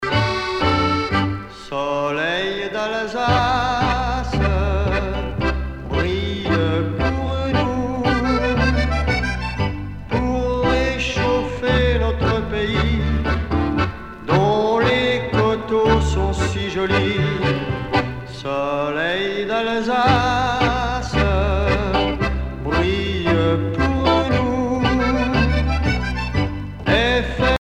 danse : tango musette
Pièce musicale éditée